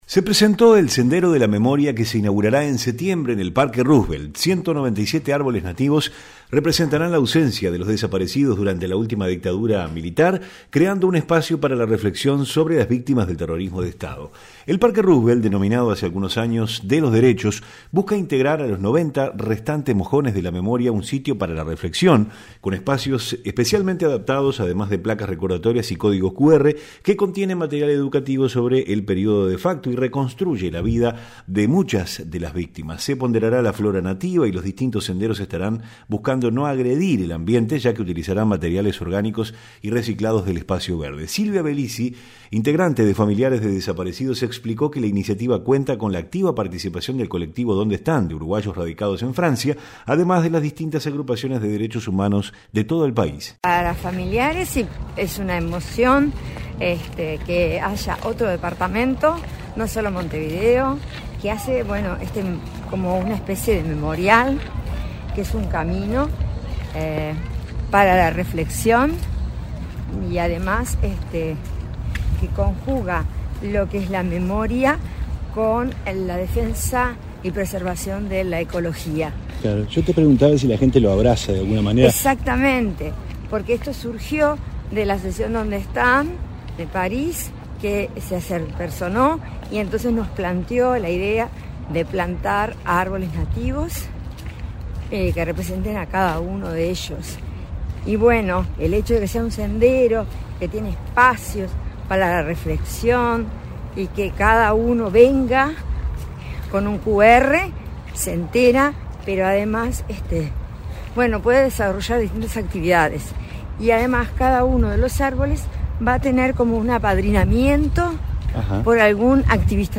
REPORTE-SENDERO-DE-LA-MEMORIA.mp3